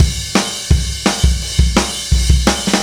Indie Pop Beat 05 Fill A.wav